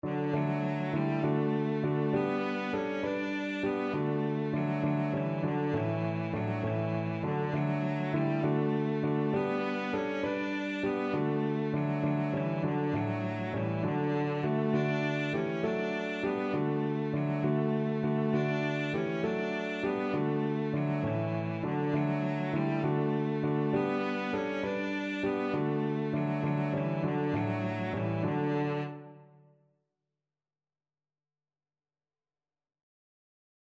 6/8 (View more 6/8 Music)
D4-D5
Classical (View more Classical Cello Music)